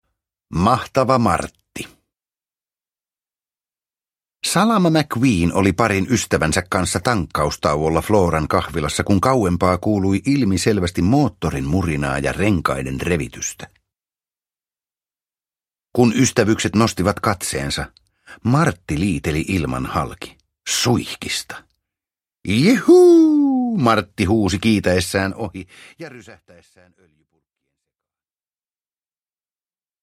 Pixar Autot. Mahtava Martti – Ljudbok – Laddas ner